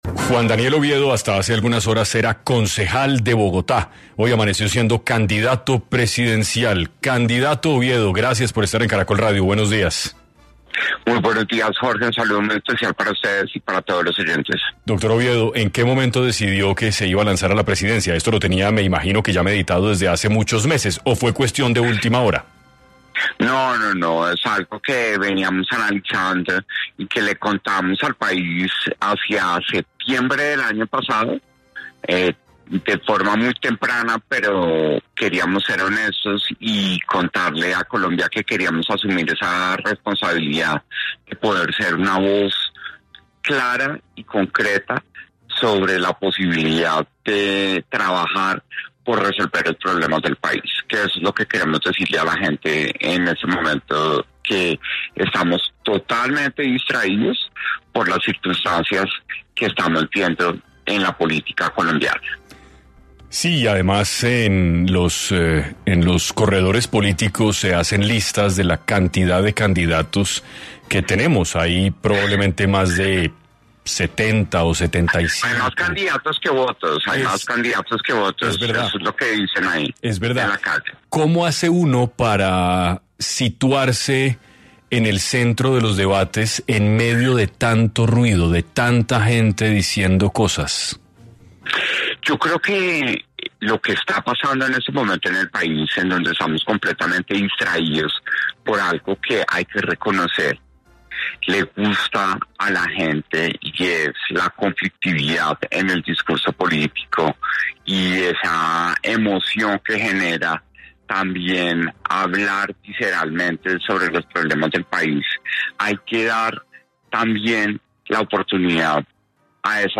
El candidato se refirió a su tan popular “acento gomelo”, como una diferencia con las regiones que en vez de alejar puede acercar a las personas, al reconocer que cada una es distinta, pero esas particularidades pueden tener un propósito en común de construir un mejor país.